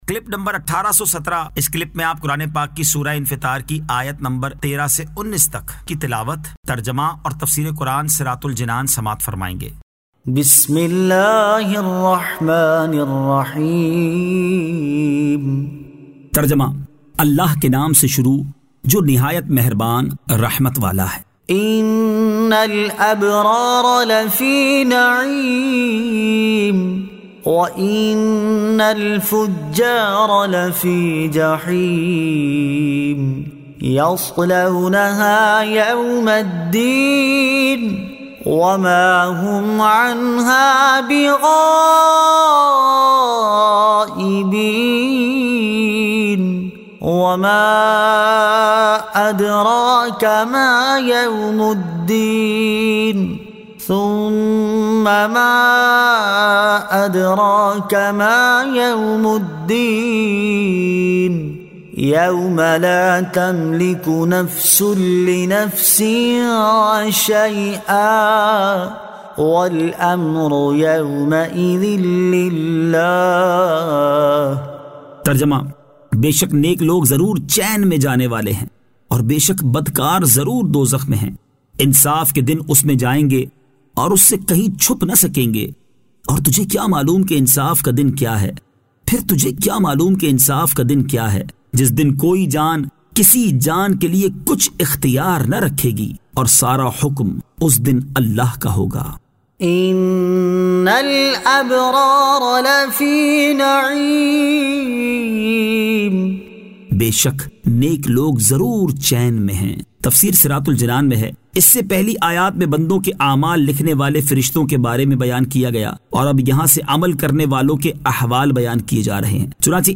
Surah Al-Infitar 13 To 19 Tilawat , Tarjama , Tafseer